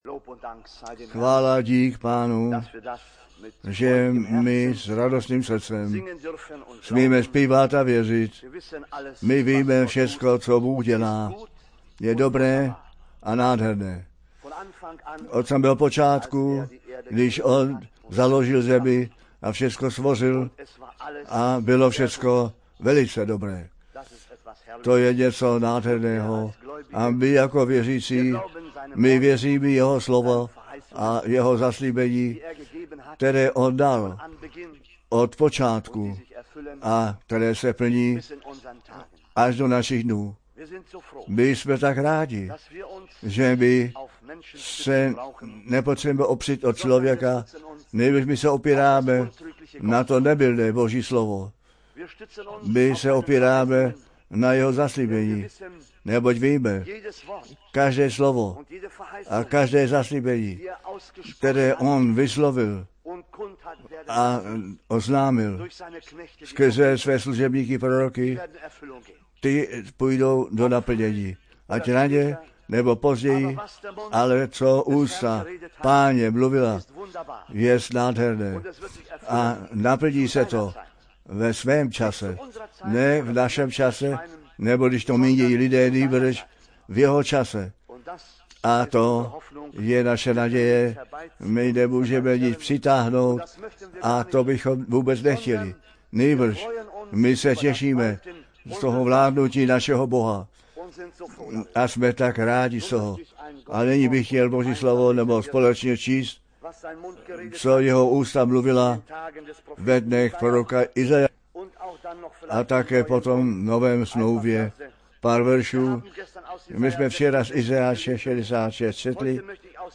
Nejnovější MP3 kázání